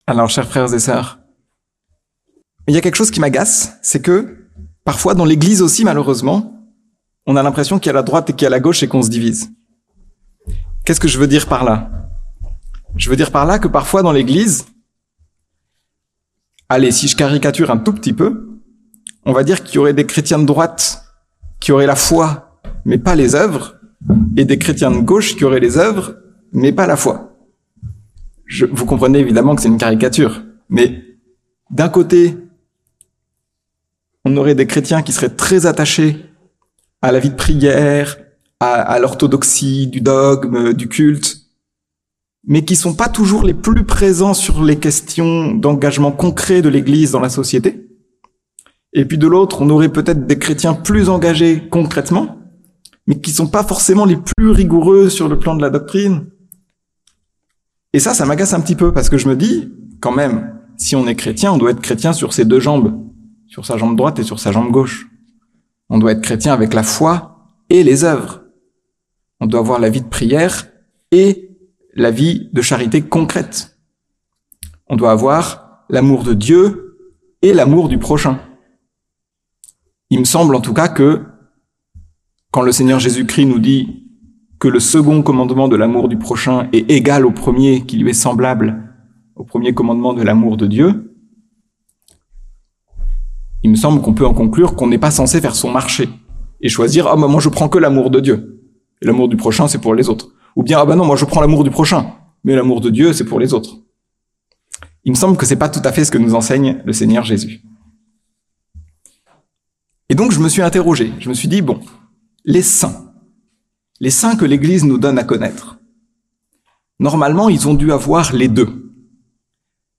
Toulon - Adoratio 2024